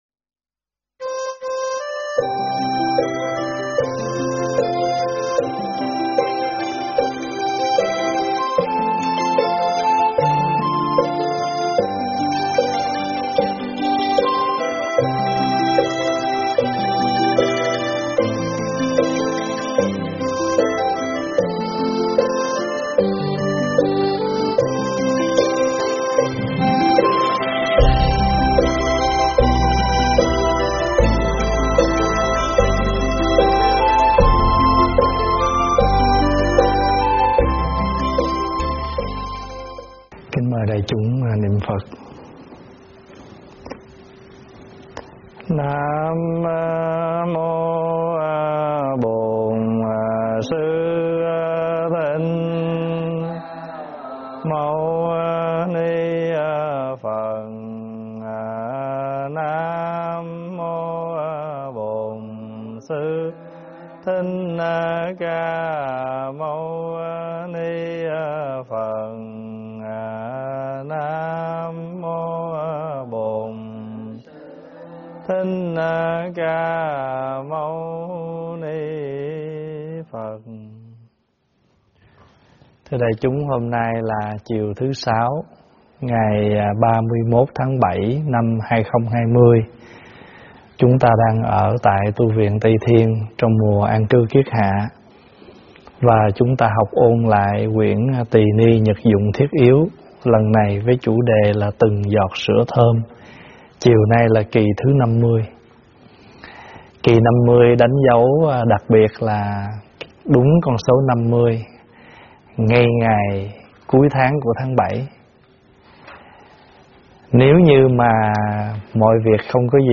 Nghe mp3 thuyết pháp Từng Giọt Sữa Thơm 50 - Kệ phu đơn tọa thiền do ĐĐ. Thích Pháp Hòa giảng tại Tv Trúc Lâm, Ngày 31 tháng 7 năm 2020
Thích Pháp Hòa giảng tại Tv Trúc Lâm, Ngày 31 tháng 7 năm 2020 Mp3 Thuyết Pháp Thích Pháp Hòa 2020 Thuyết Pháp Thích Pháp Hòa